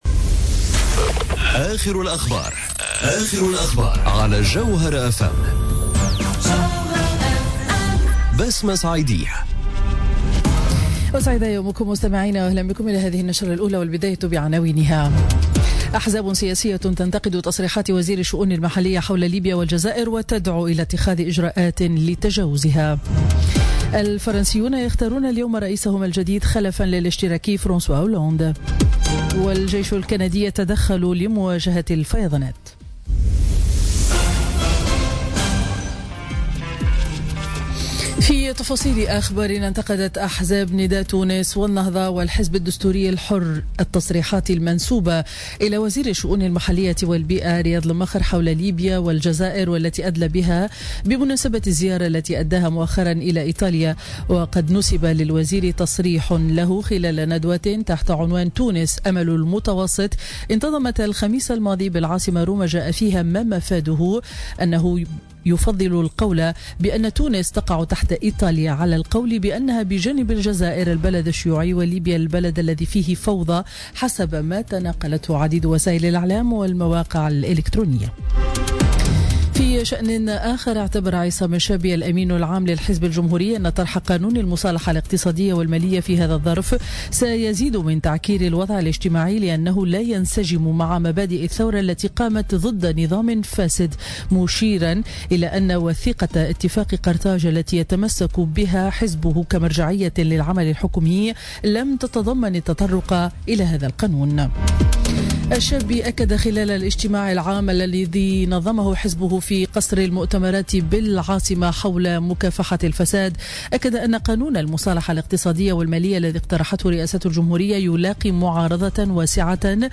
نشرة أخبار السابعة صباحا ليوم الأحد 7 ماي 2017